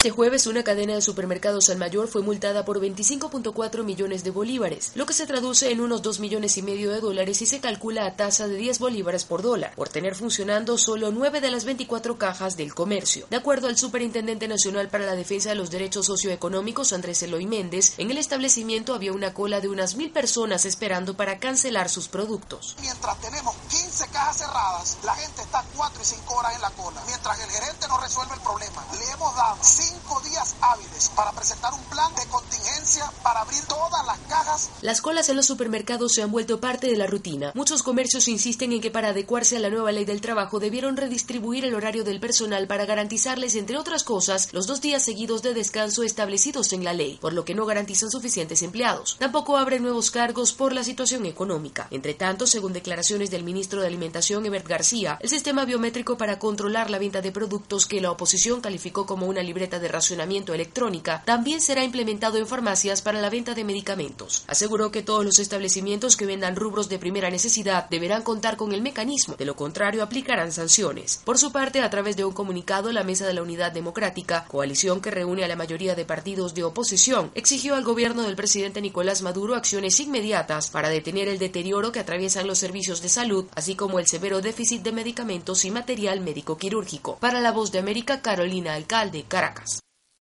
El gobierno de Venezuela continua implementando controles que de acuerdo a integrantes del sector económico asfixian a los comercios y no representan soluciones a los problemas. Desde Caracas informa